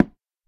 Minecraft / dig / wood2.ogg
wood2.ogg